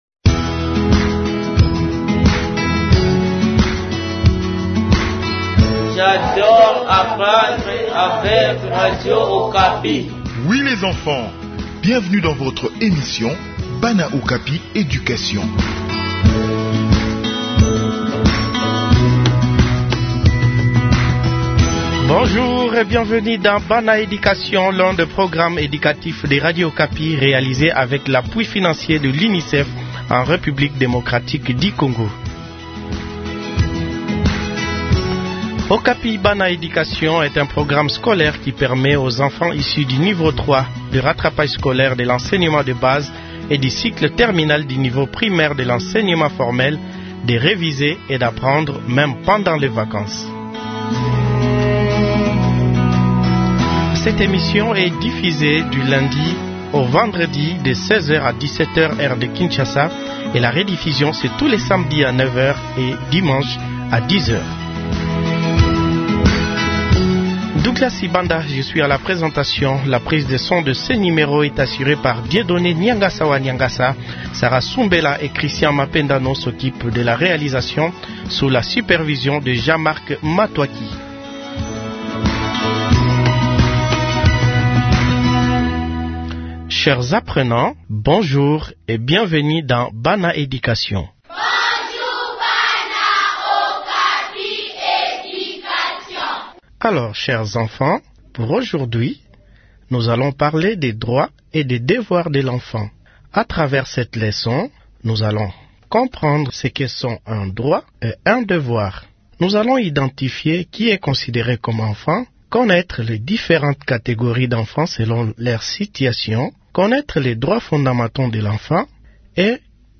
Enseignement à distance : leçon sur les droits et les devoirs des enfants